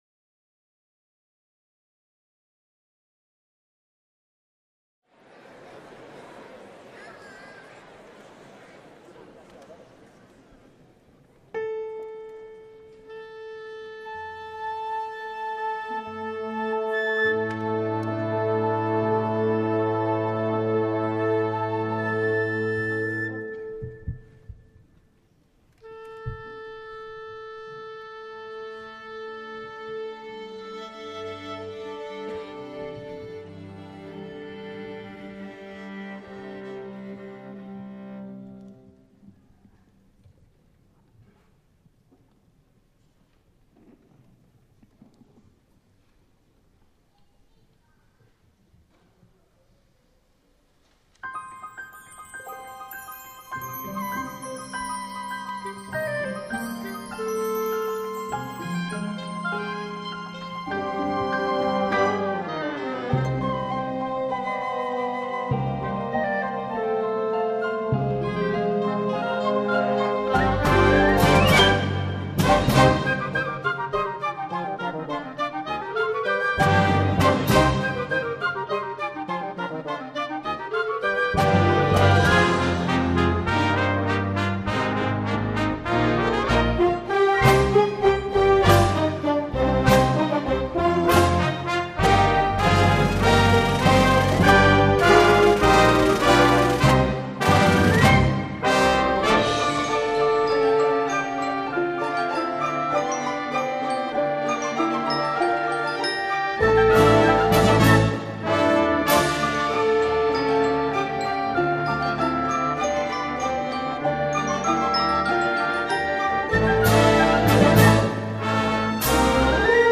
Christmas Concert 2024